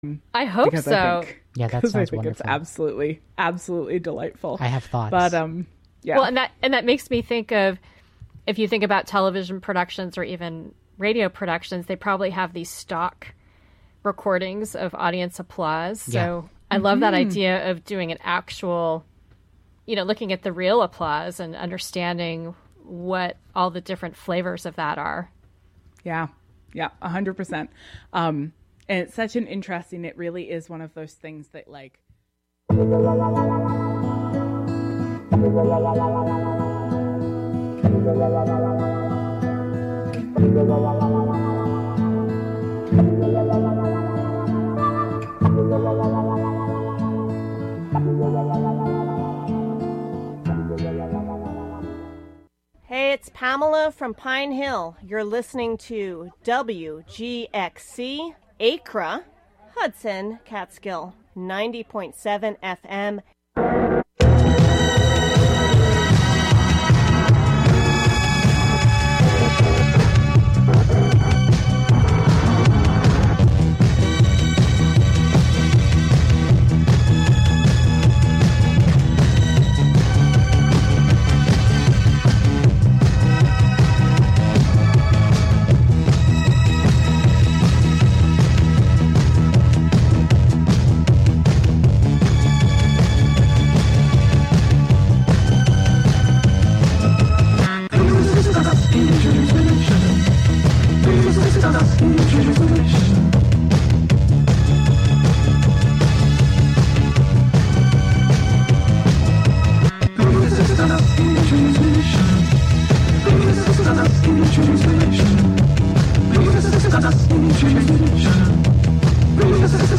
Tune in for the latest episode of the weekly serial, "I Have Seen Niagara." This week: S1 EP5 - The Sunflower Cult, part 5: Alone with a Living Body. In our full-length season one finale, a young man accompanies his uncle across the border, but he stops moving and speaking so he thinks he's dead.